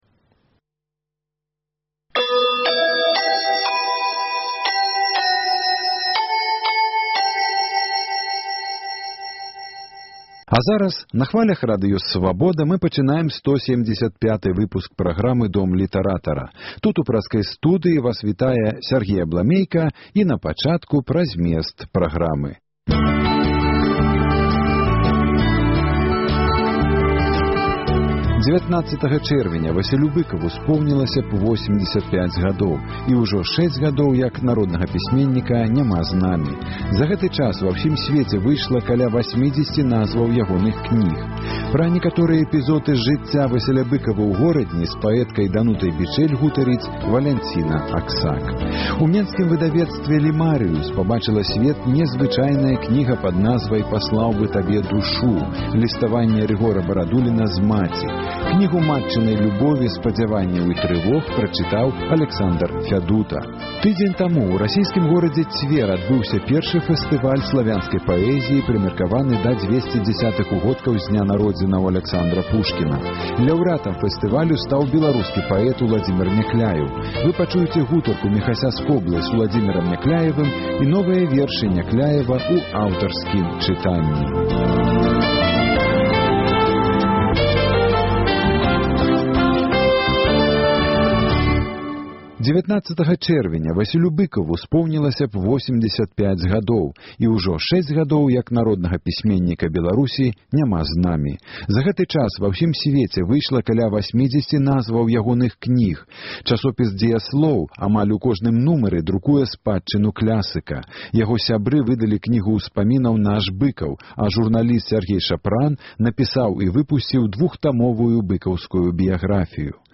Размова з паэткай Данутай Бічэль пра Васіля Быкава, развагі Аляксандра Фядуты пра кнігу перапіскі Рыгора Барадуліна з маці “Паслаў бы табе душу" і гутарка з Уладзімерам Някляевым і ягоныя новыя вершы ў аўтарскім чытаньні